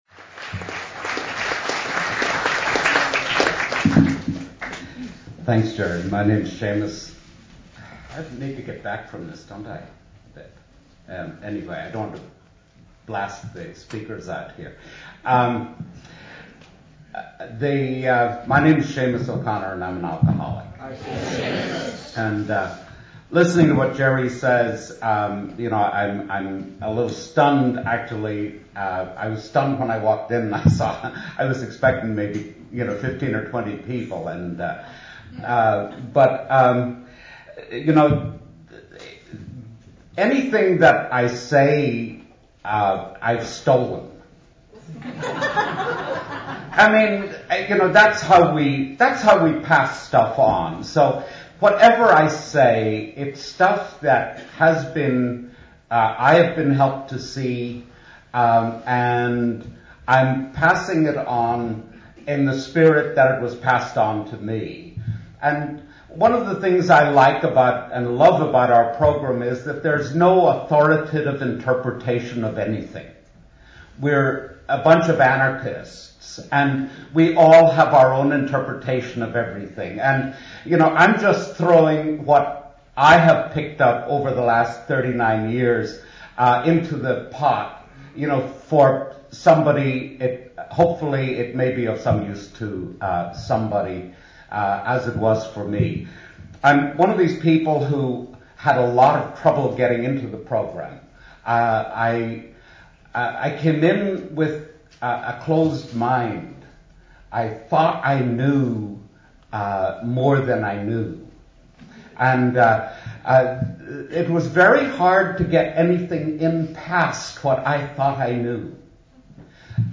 San Diego Spring Roundup, 2009 – Popular AA Speakers